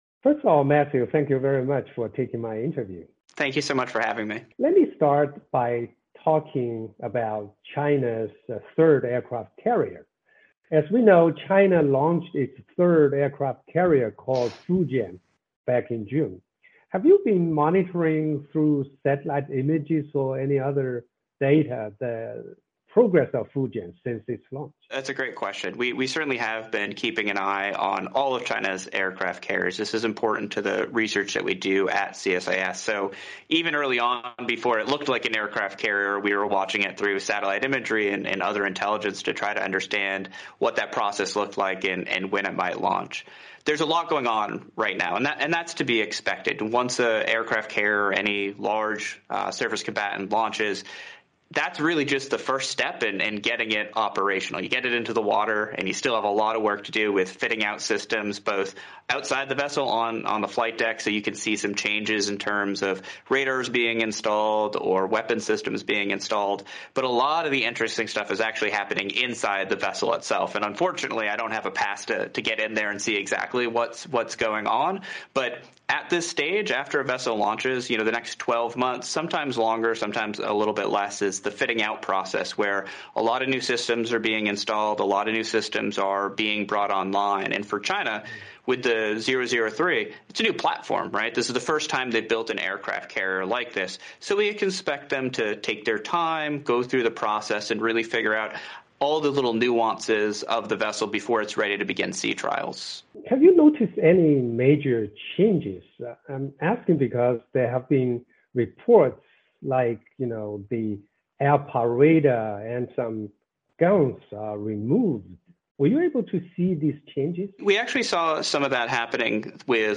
VOA专访: 军事专家谈中国航母“福建号”